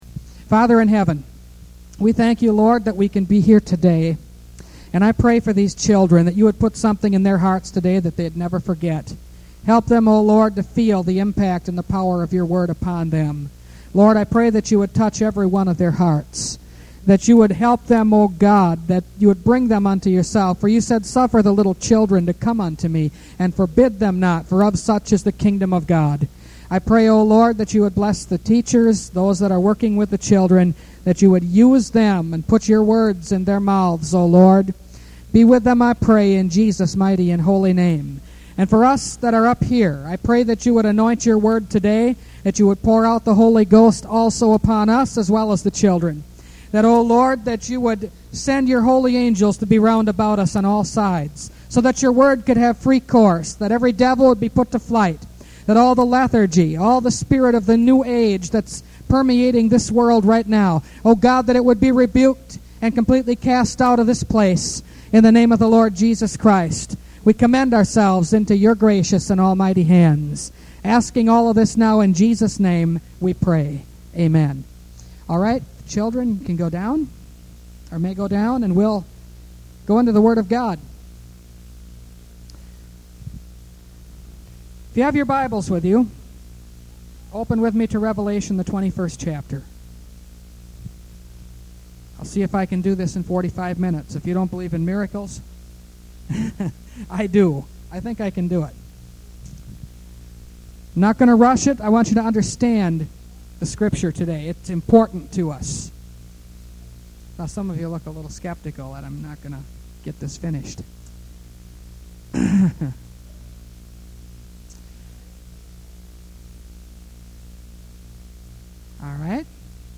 Revelation Series – Part 45 – Last Trumpet Ministries – Truth Tabernacle – Sermon Library